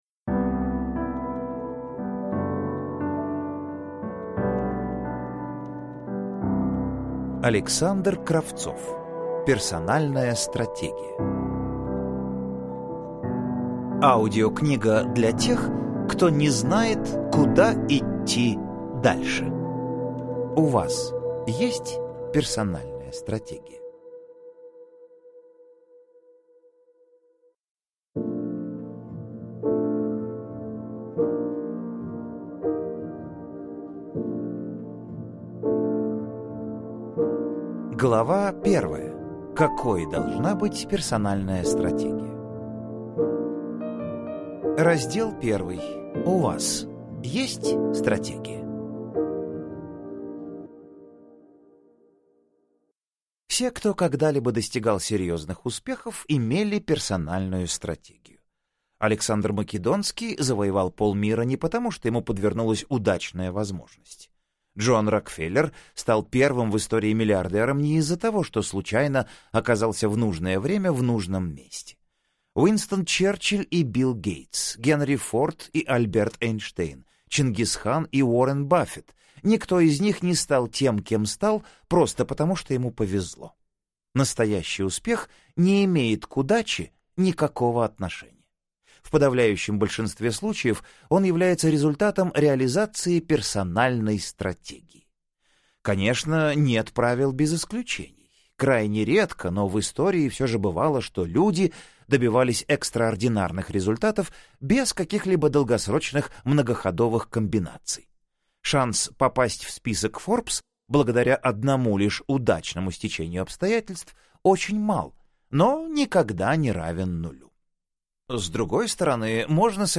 Аудиокнига Персональная стратегия: Книга для тех, кто не знает куда идти дальше | Библиотека аудиокниг